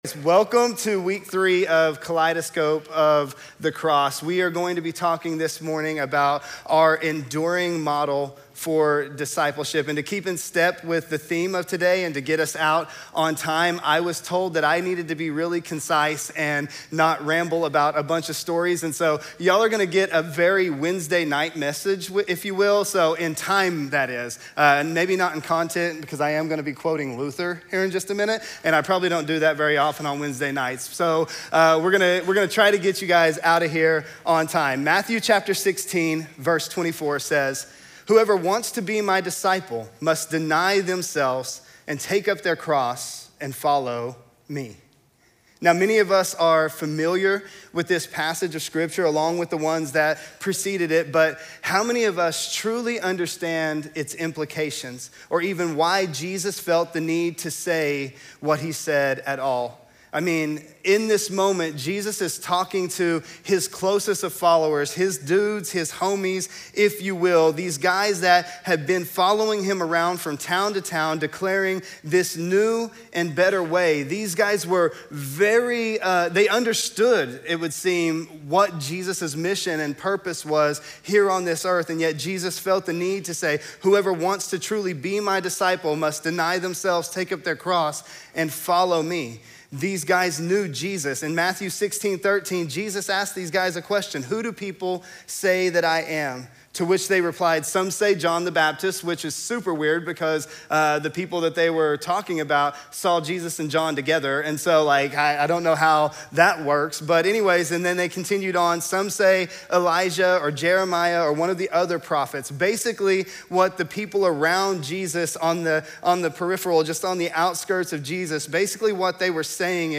A Farewell Interview